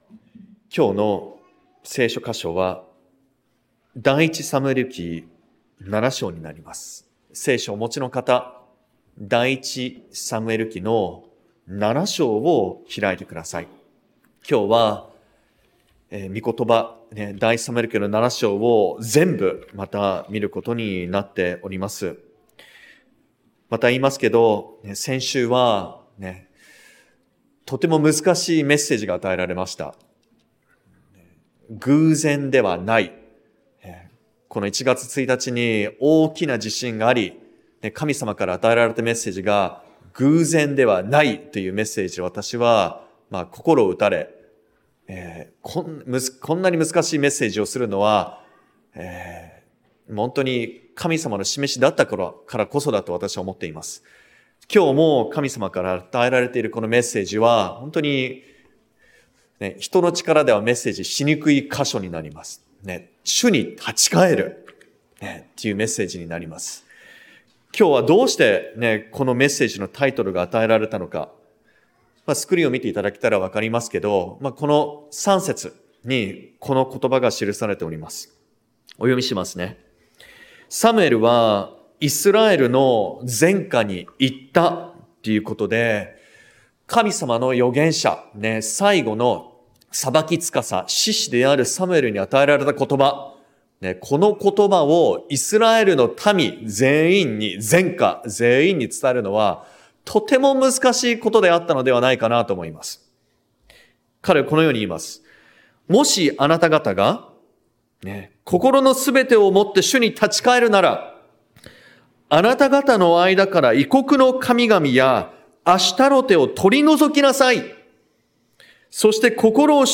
主に立ち返る 説教者